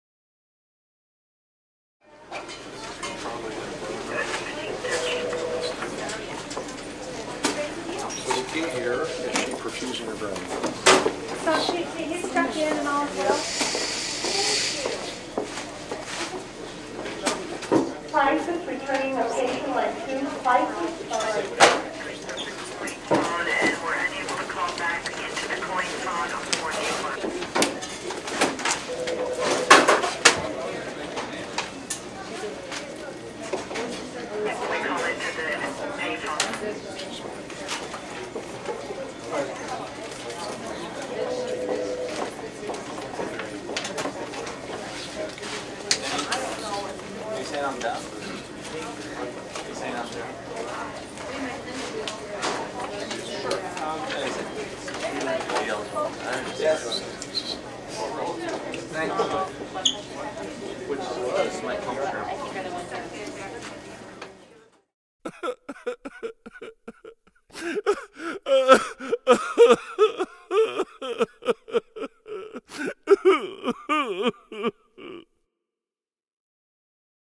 Hospital Sound EffectHospital AmbienceHi Resolution Audio